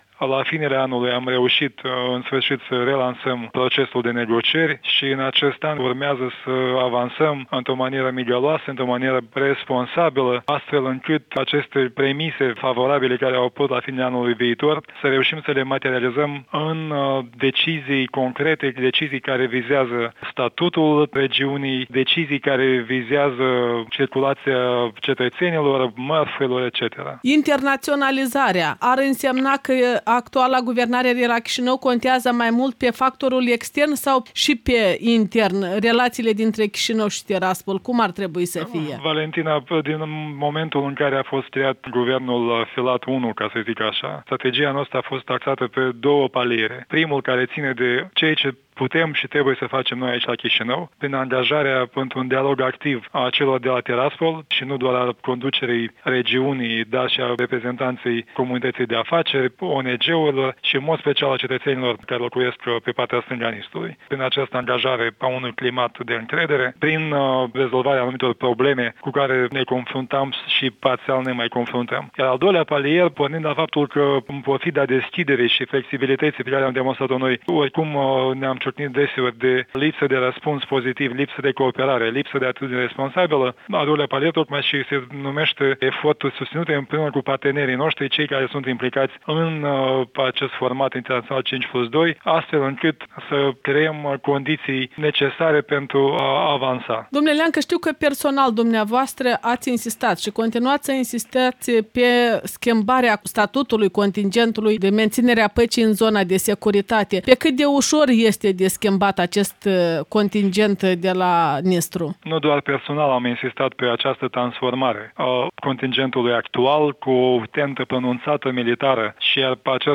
Un interviu cu ministrul de externe Iurie Leancă.